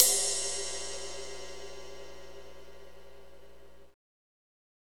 Index of /90_sSampleCDs/Northstar - Drumscapes Roland/DRM_Slow Shuffle/CYM_S_S Cymbalsx